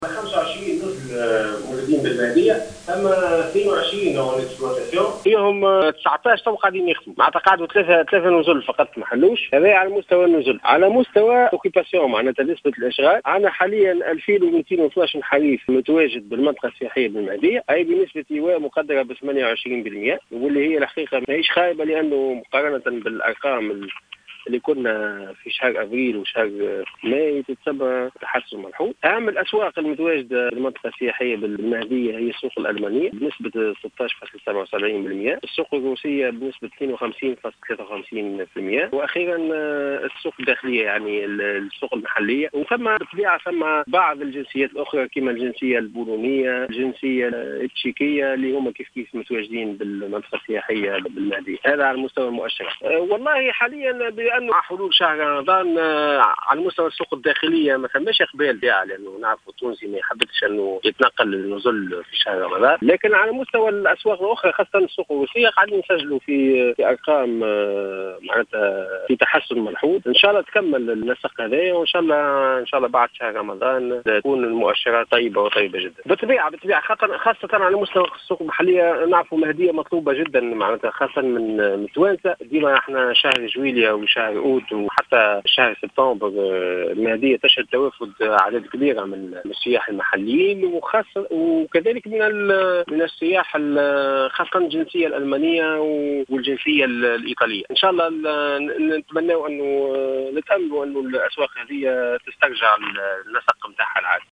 أكد المندوب الجهوي للسياحة بالمهدية مهدي حلوان في تصريح للجوهرة أف أم اليوم الأربعاء 8 جوان 2016 أن المؤشرات السياحية لهذا الموسم في تحسن ملحوظ.